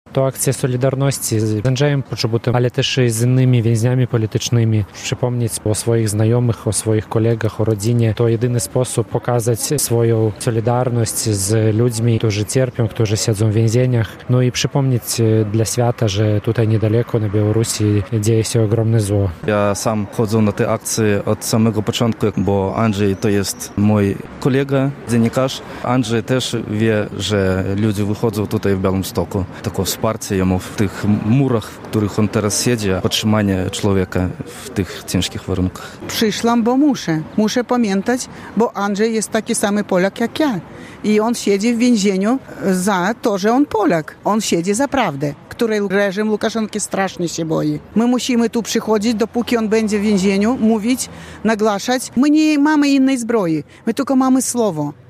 Jak co miesiąc białostoczanie spotkali się na skwerze przy pomniku Jerzego Popiełuszki w Białymstoku, by upominać się o wolność dla Andrzeja Poczobuta i innych więźniów politycznych.
relacja